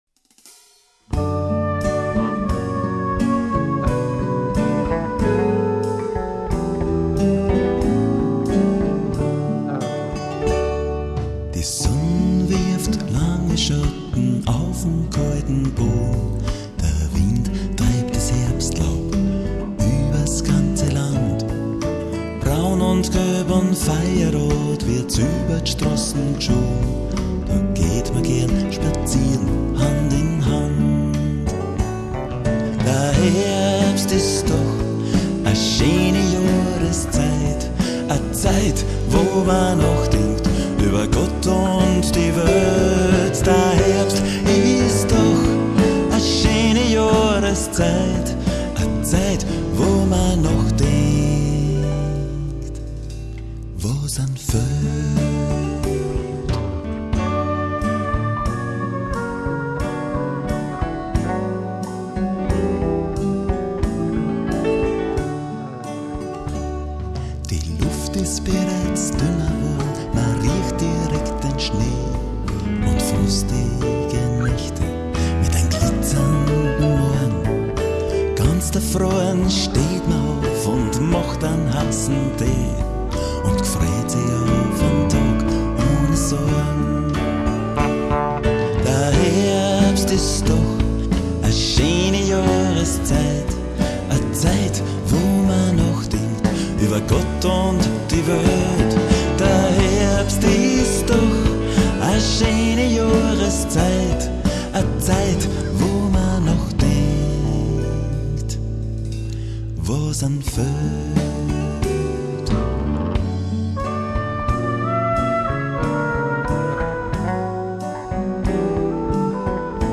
E-Bass
Drums
Keyboard
Percussion